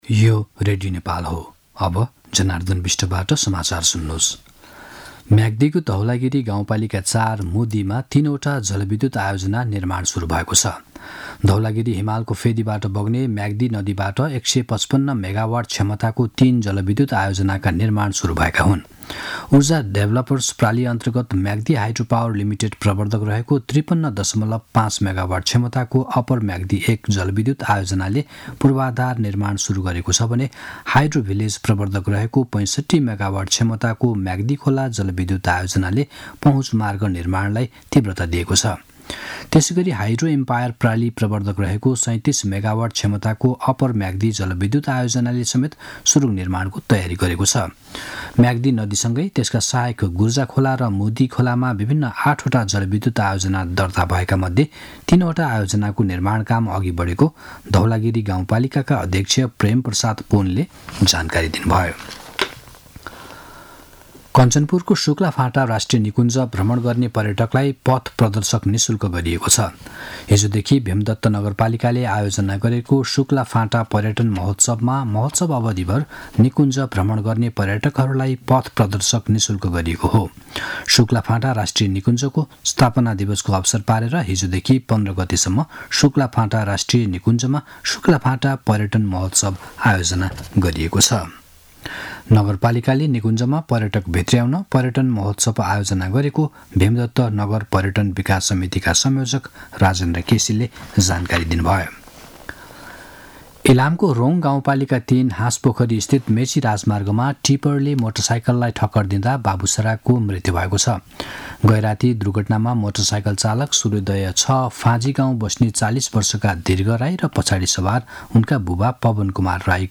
मध्यान्ह १२ बजेको नेपाली समाचार : ११ फागुन , २०८१